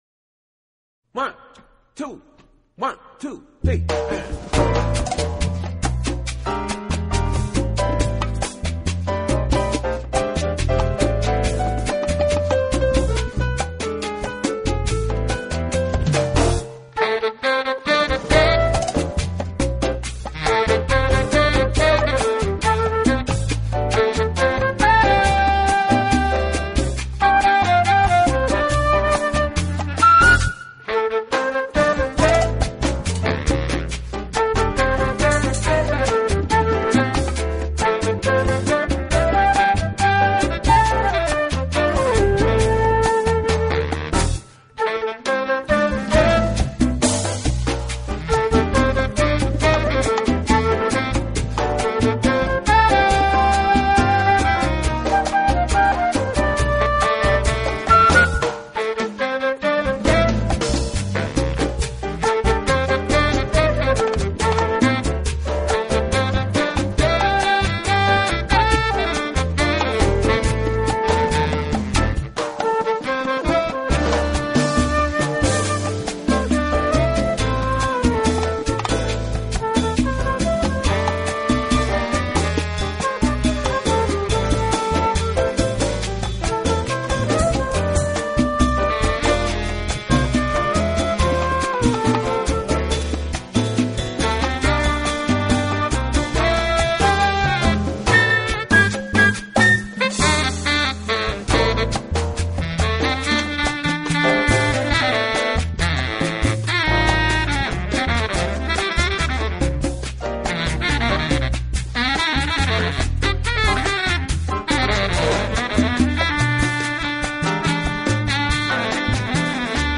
flute
bass instrument
percussion
piano